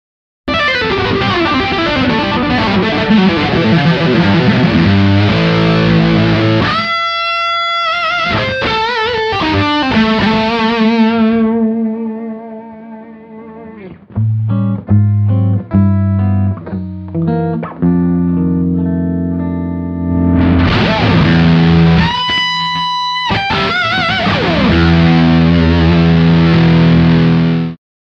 The EHX OD Glove provides a rich, overtone laden sound that doesn't get muddy.
Dynamic Range Using Guitar Volume
OD-GloveMOSFET-Overdrive-Distortion-Dynamic-Range-Using-Guitar-Volume.mp3